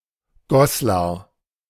Goslar (German pronunciation: [ˈɡɔslaʁ]
De-Goslar.ogg.mp3